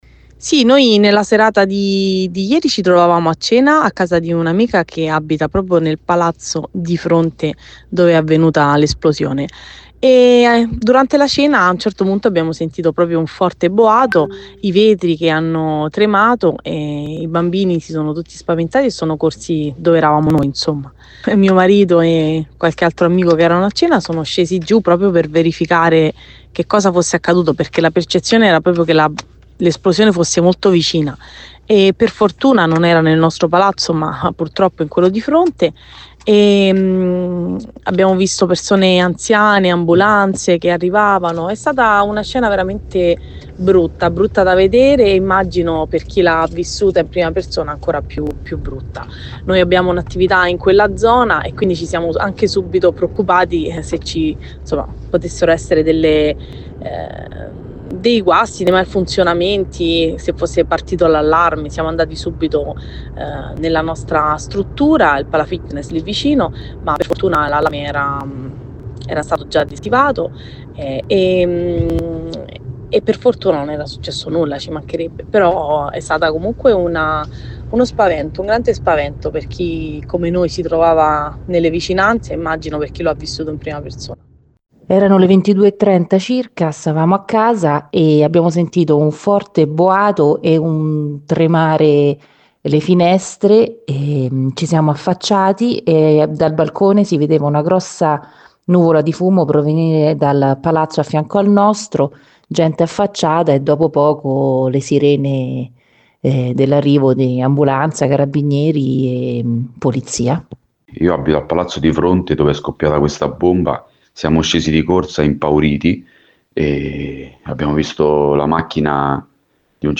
Le altre testimonianze di altri residenti
RESIDENTI.mp3